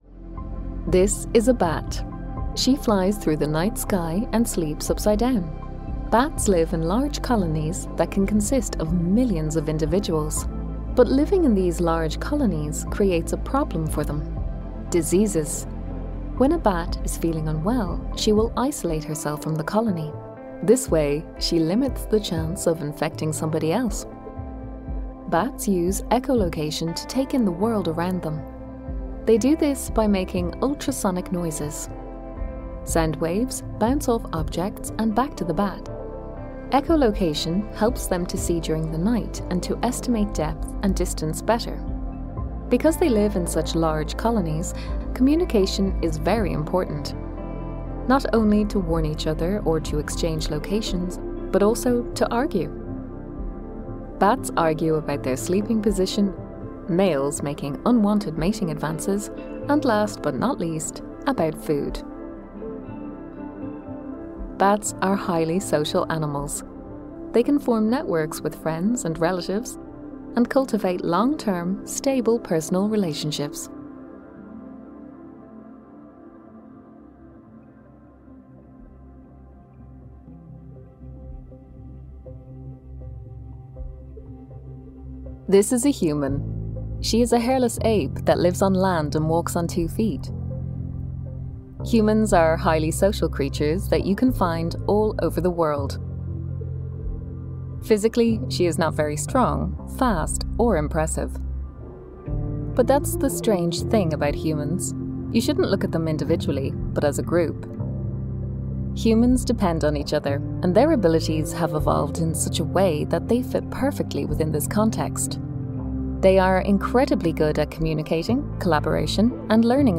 Female
Natural, Smooth, Warm
Neutral Irish (native), Dublin (native), RP, Standard British, General London, Californian, Standard US
Microphone: Rode NT2